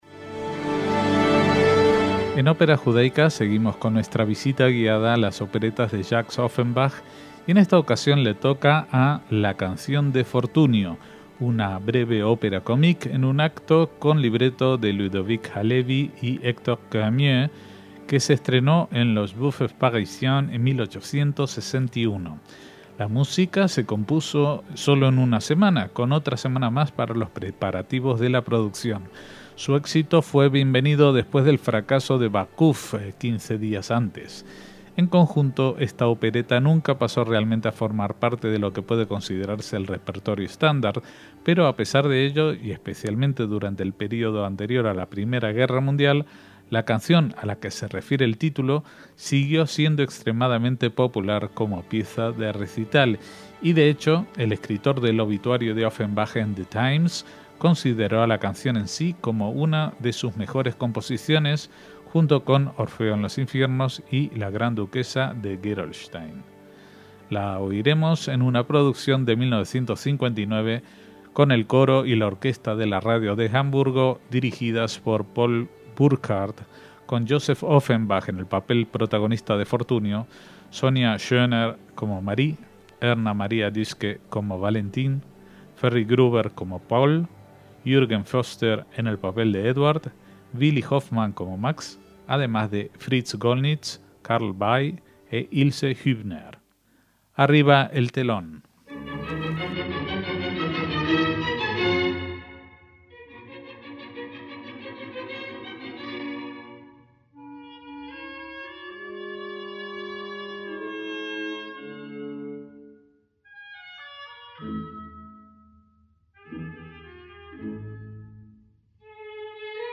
ópera cómica breve en un acto